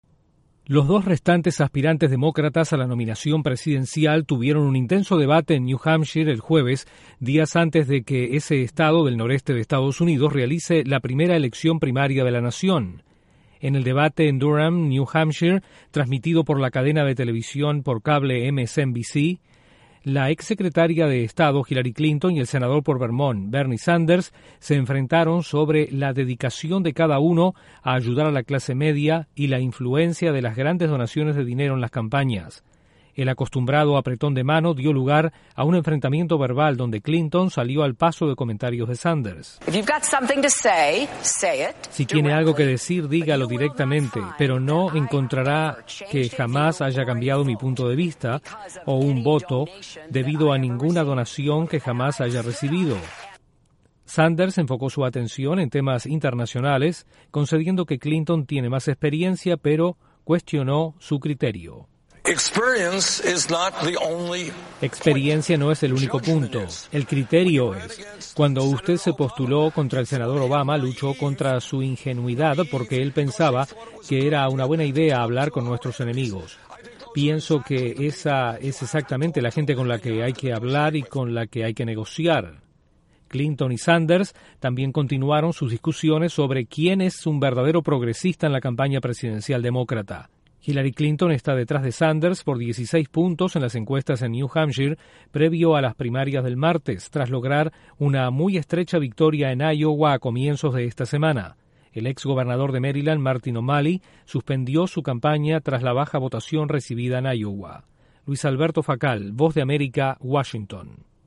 Los candidatos a la nominación presidencial demócrata realizaron un combativo debate previo a las primarias de New Hampshire que tendrán lugar el martes próximo. Desde la Voz de América en Washington informa